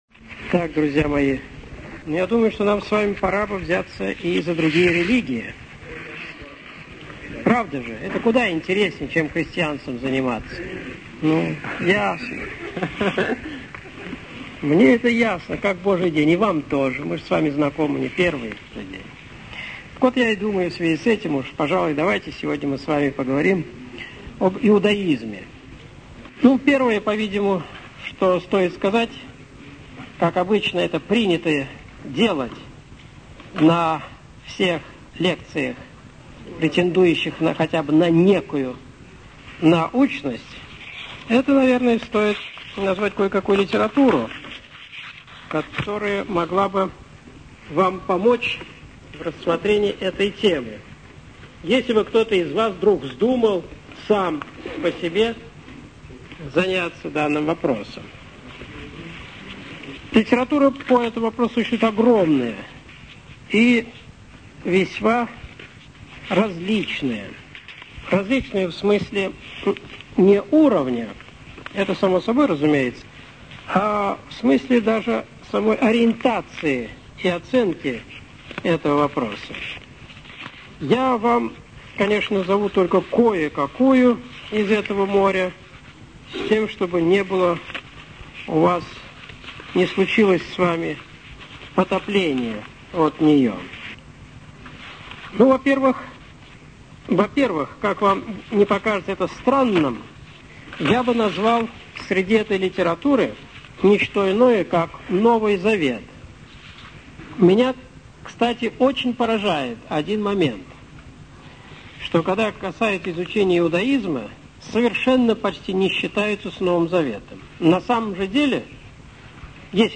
Иудаизм (Лекция 24. Основное богословие, 2000-2001)